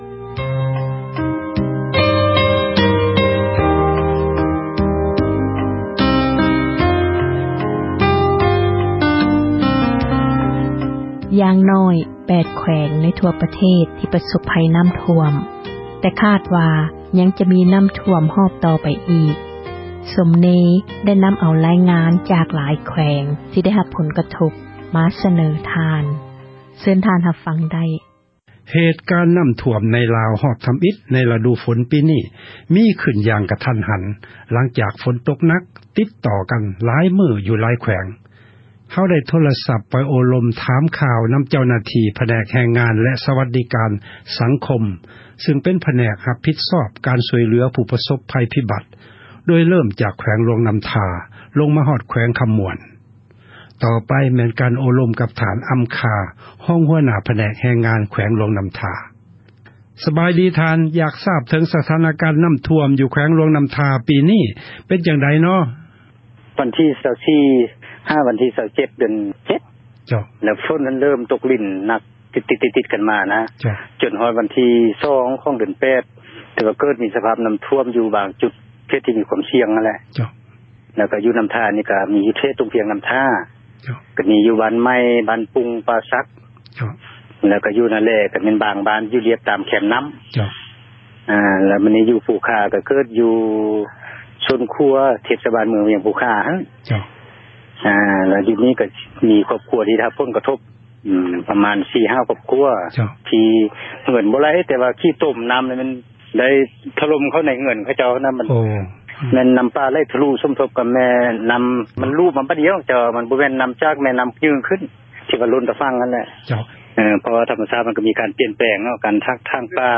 ເຫດການ ນໍ້າຖ້ວມ ໃນລາວ ຮອບ ທໍາອິດ ໃນ ລະດູຝົນ ປີນີ້ ເກີດຂຶ້ນ ຢ່າງ ກະທັນຫັນ, ຫລັງຈາກ ຝົນຕົກ ໜັກ ຕິດຕໍ່ກັນ ຫຼາຍມື້ ຢູ່ ຫຼາຍແຂວງ. ກ່ຽວກັບ ສະພາບການ ນໍ້າຖ້ວມ ຄັ້ງນີ້, ເຮົາໄດ້ ໂທຣະສັບ ໄປ ໂອ້ລົມ ຖາມຂ່າວ ນໍາ ເຈົ້າໜ້າທີ່ ຜແນກ ແຮງງານ ແລະ ສວັດດີການ ສັງຄົມ ຊຶ່ງ ເປັນ ພາກສ່ວນ ຮັບຜິດຊອບການ ຊ່ວຍເຫລືອ ຜູ້ ປະສົບ ພັຍ ໂດຍ ເລີ້ມຈາກ ແຂວງ ຫຼວງນໍ້າທາ ລົງມາ ຮອດ ແຂວງ ຄໍາມ່ວນ.